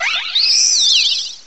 cry_not_ribombee.aif